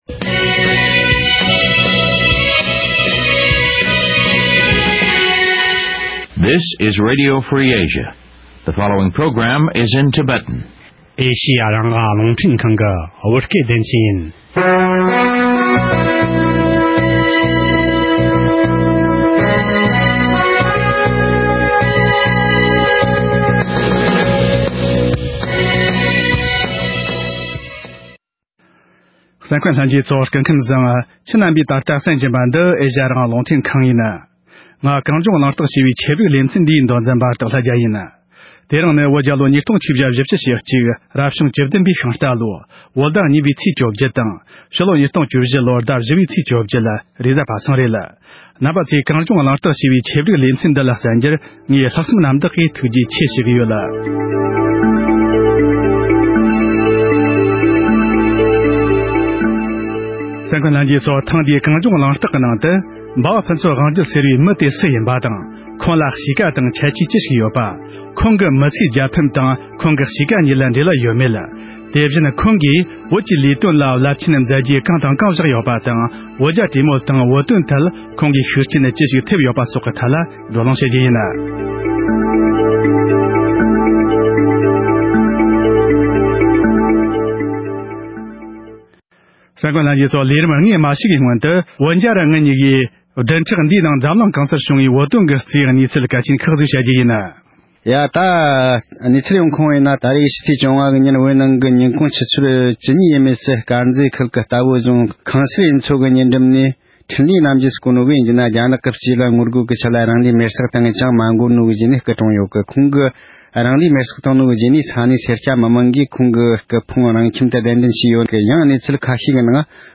དེ་བཞིན་ཁོང་གིས་བོད་ཀྱི་ལས་དོན་ལ་རླབས་ཆེན་མཛད་རྗེས་གང་དང་གང་བཞག་ཡོད་པ་དང་། བོད་རྒྱ་གྲོས་མོལ་དང་བོད་དོན་ཐད་ཁོང་གིས་ཤུགས་རྐྱེན་ཅི་ཞིག་ཐེབས་ཡོད་པ་སོགས་ཀྱི་ཐད་བགྲོ་གླེང་ཞུས་པ་ཞིག་གསན་རོགས་གནང་།།